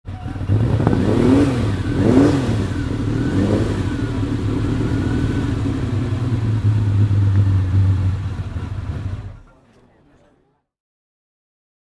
Ferrari 250 LM (1964) - Starten am Concorso d'Eleganza Villa d'Este